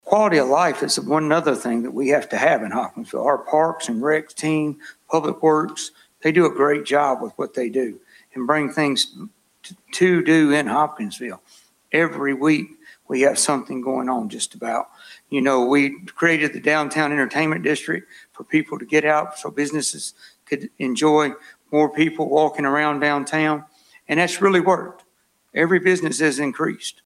The League of Women Voters hosted the candidates Monday night for its final forum of the night.